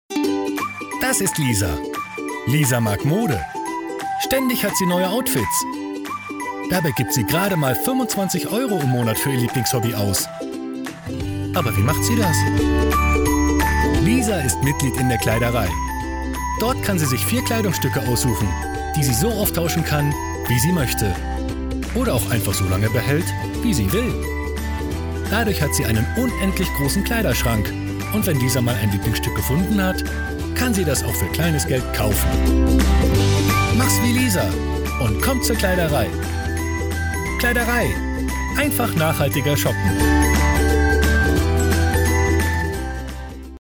– Educational Style –